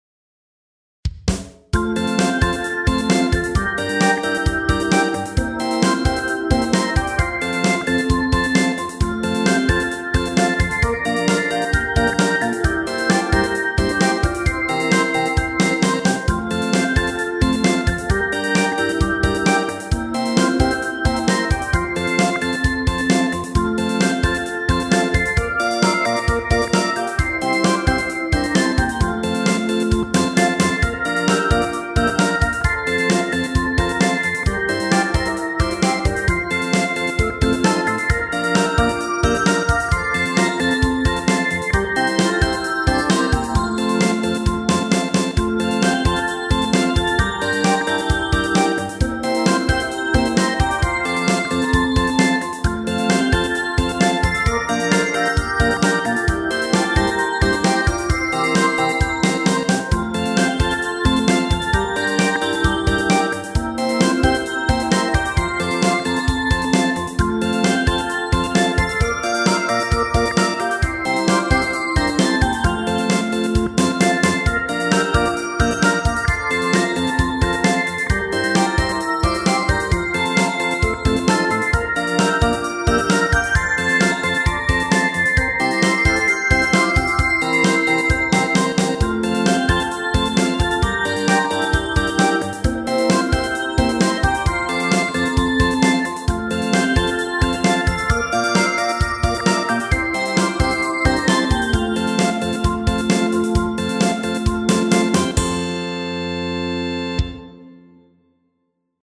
アップテンポインストゥルメンタルエレクトロニカ